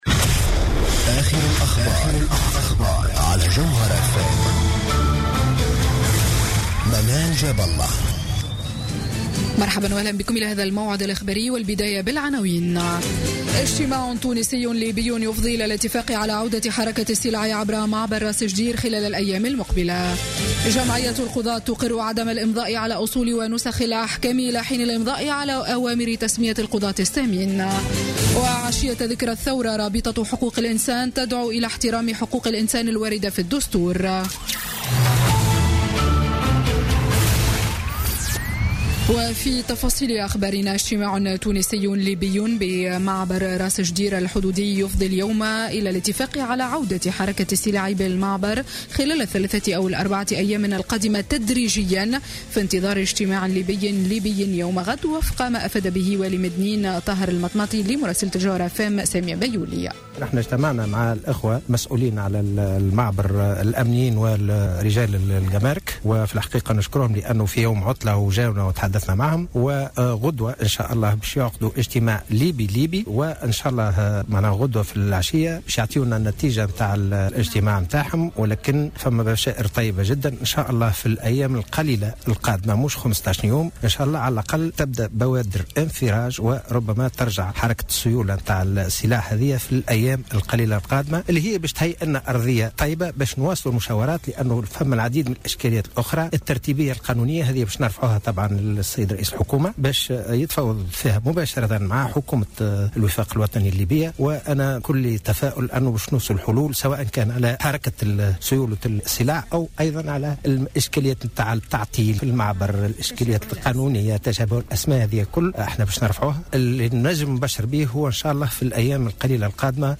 نشرة أخبار السابعة مساء ليوم الجمعة 13 جانفي 2017